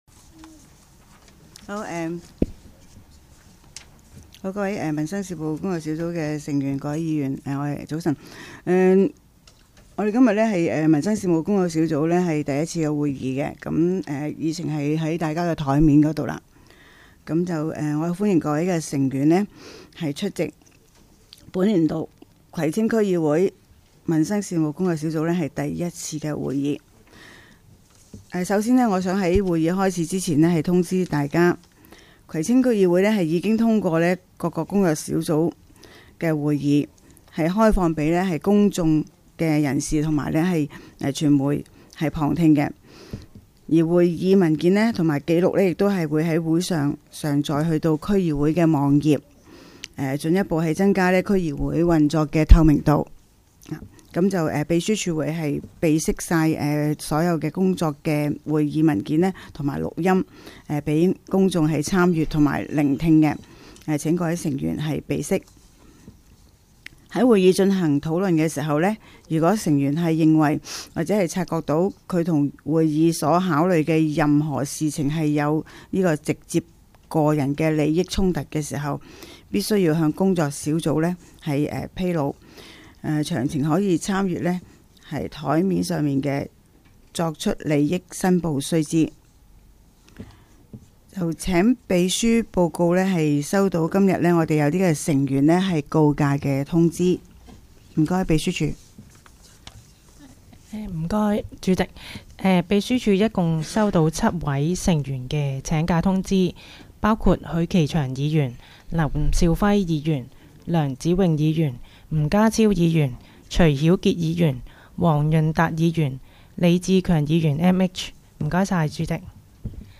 工作小组会议的录音记录